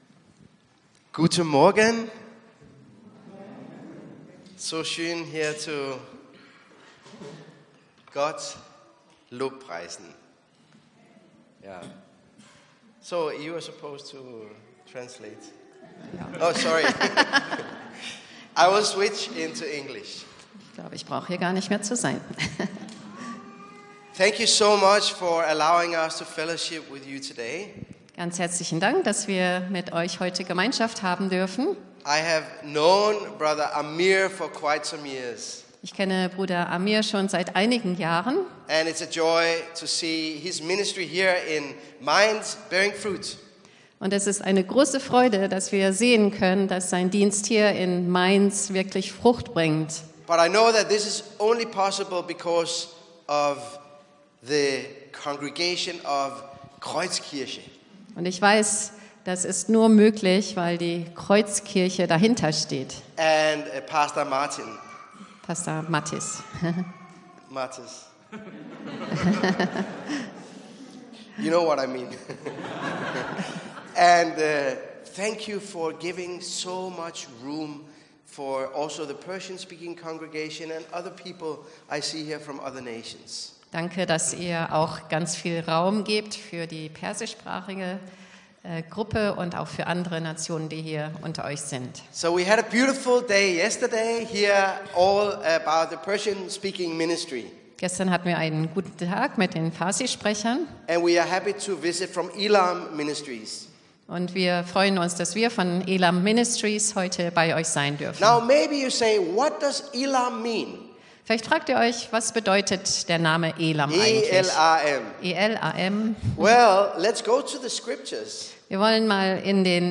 Predigt vom 25.05.2025